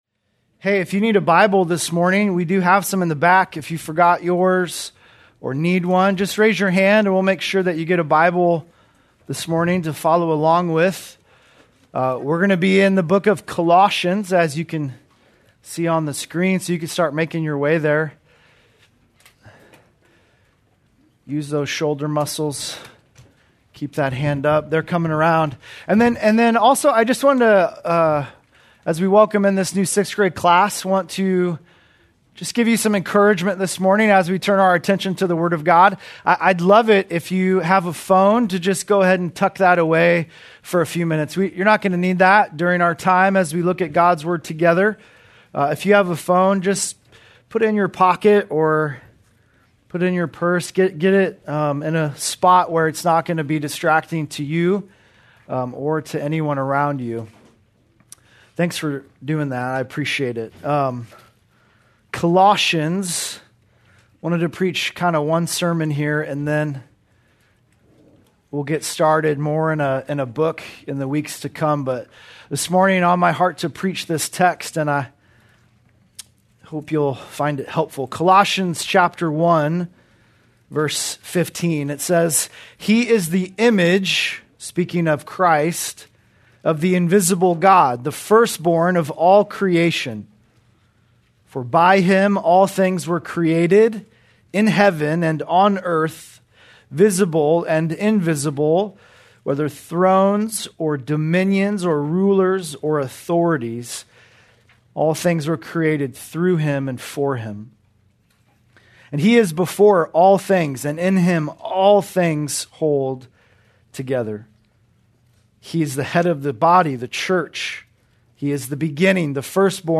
August 31, 2025 - Sermon